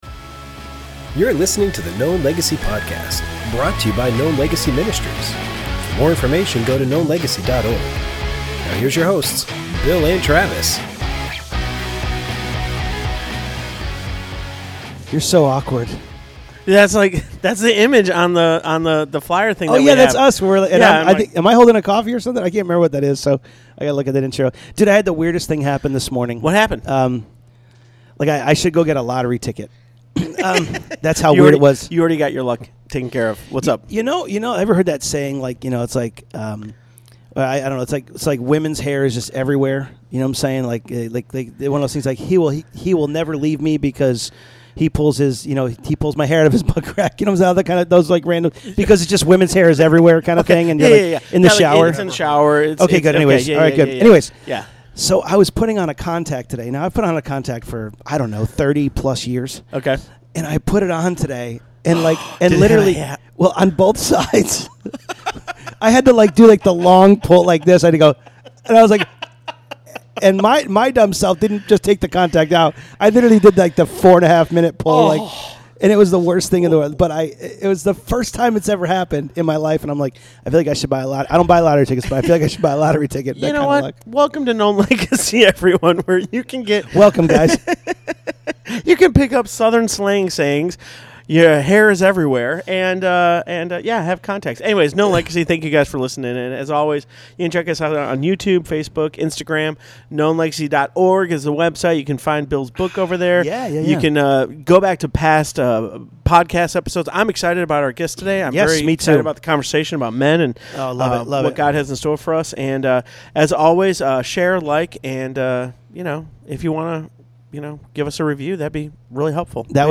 Forgiving the unforgivable - Interview